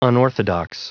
Prononciation du mot unorthodox en anglais (fichier audio)
Vous êtes ici : Cours d'anglais > Outils | Audio/Vidéo > Lire un mot à haute voix > Lire le mot unorthodox